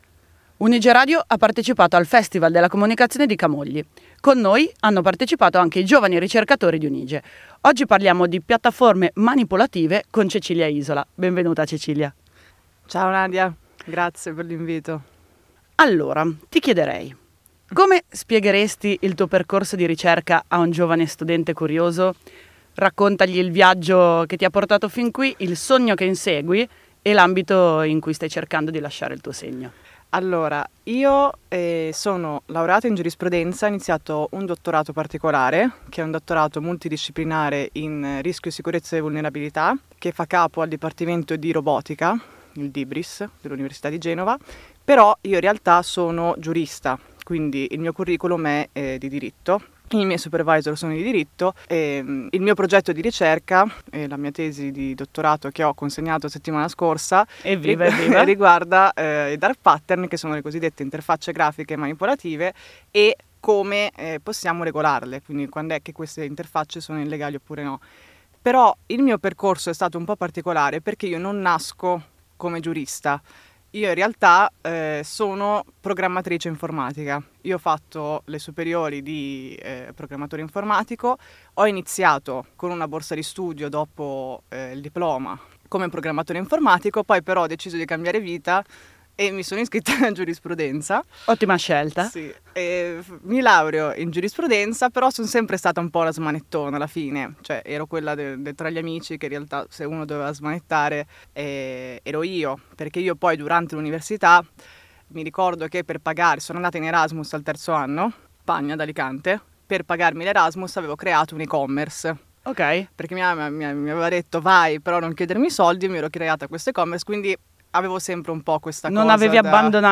Un dialogo che intreccia ricerca, sfide accademiche e nuove prospettive imprenditoriali.